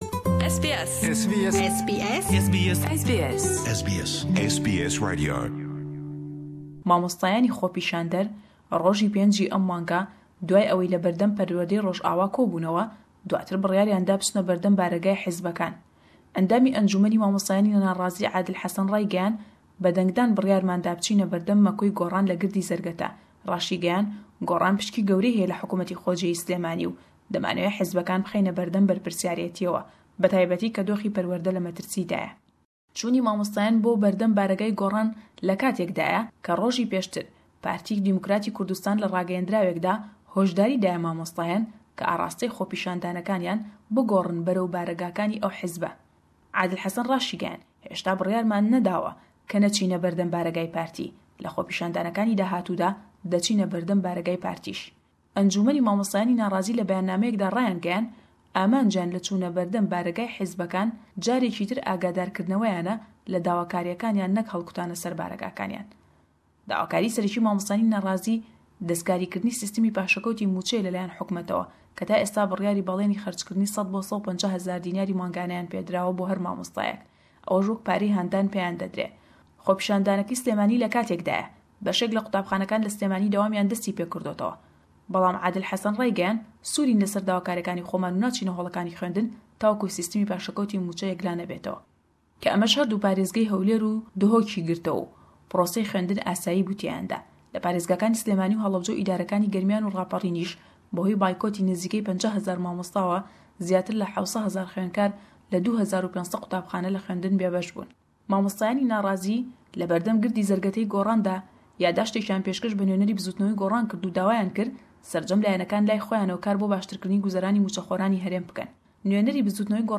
Rapora
ji Slêmaniyê li ser xwepêshandana mamosteyan û berdewambûna kêsheya ne-wergirtina mûçeyan.